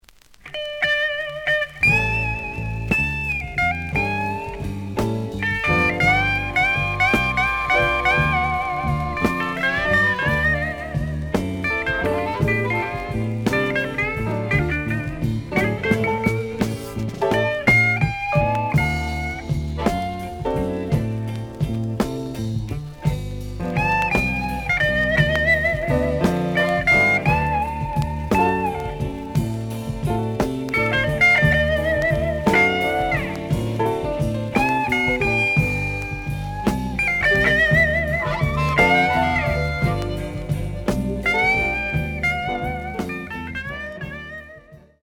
The audio sample is recorded from the actual item.
●Genre: Blues
Looks good, but slight noise on both sides.